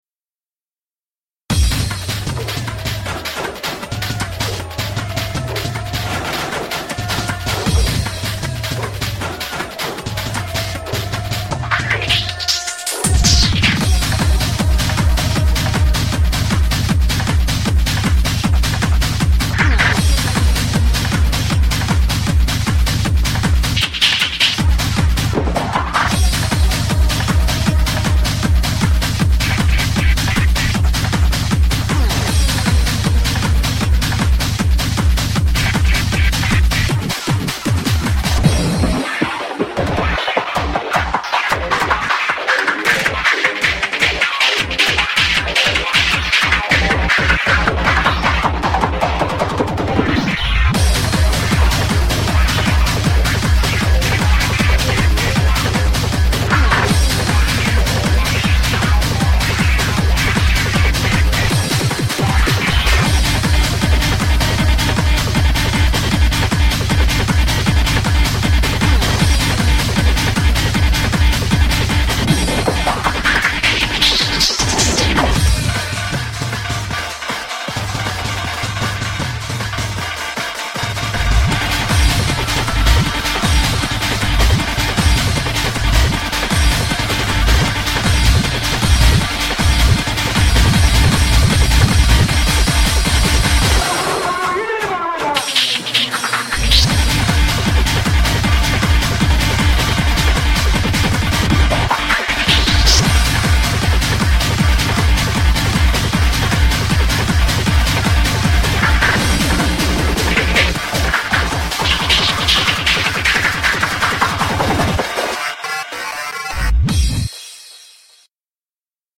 BPM78-624
Audio QualityLine Out